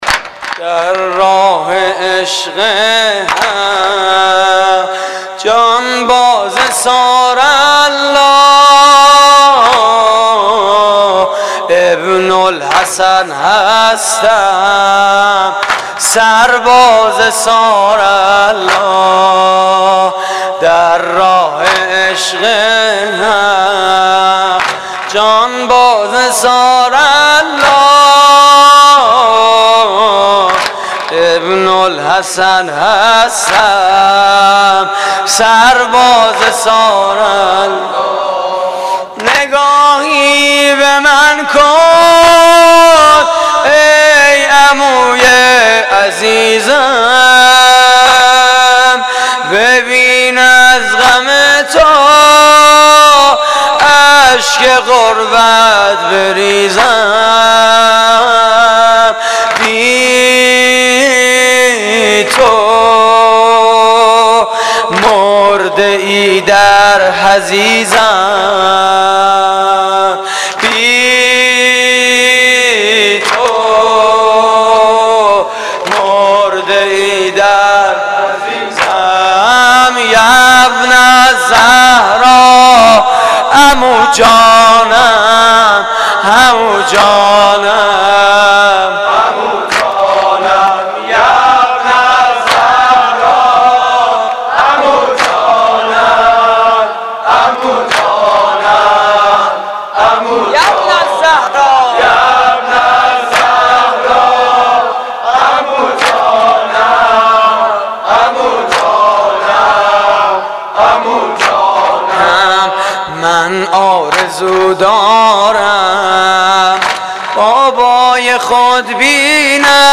واحد: سرباز ثارالله متاسفانه مرورگر شما، قابیلت پخش فایل های صوتی تصویری را در قالب HTML5 دارا نمی باشد.
مراسم عزاداری شب پنجم محرم 1432